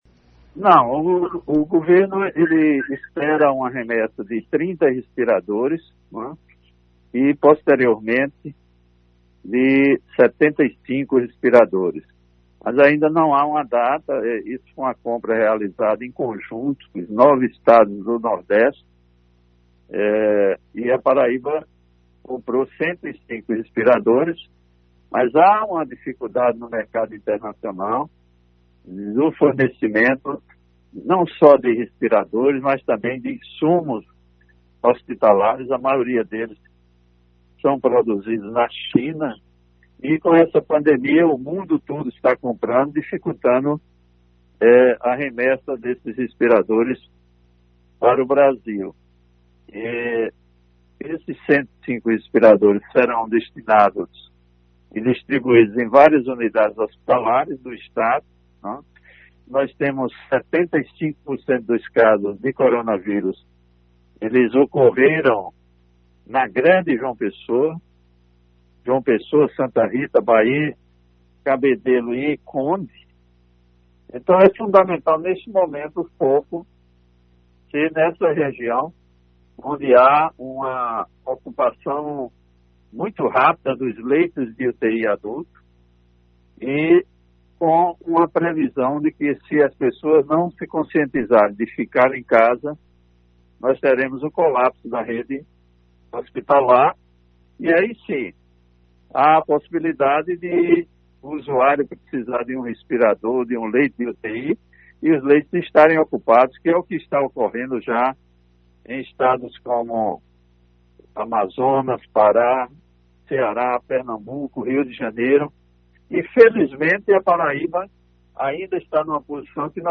Em entrevista na tarde desta quarta – feira (06), no Programa Rádio Vivo da Alto Piranhas.